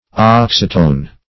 Oxytone \Ox"y*tone\, n.